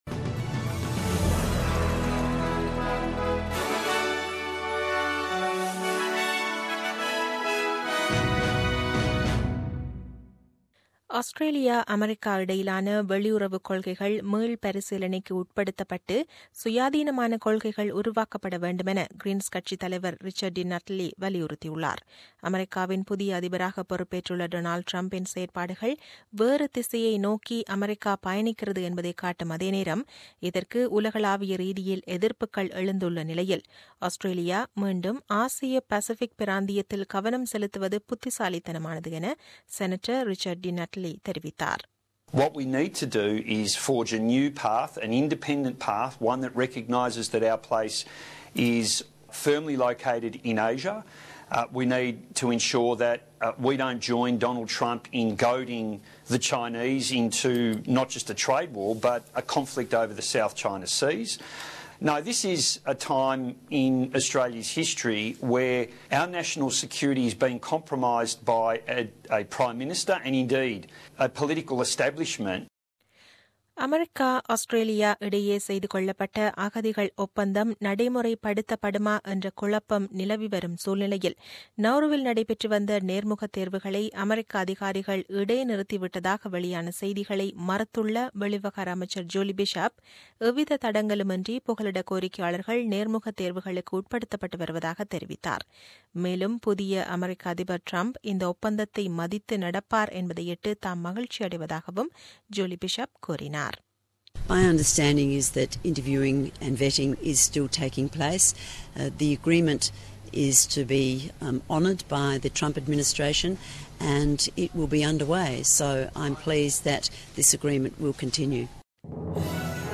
The news bulletin aired on 05 Feb 2017 at 8pm.